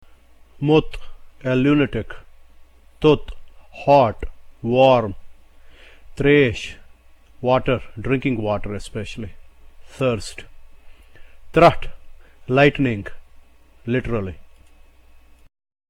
Thus the symbol T is pronounced the way one would pronounce the letter T in the name "TIBET". In Kashmiri the following are some of the words that use this sound: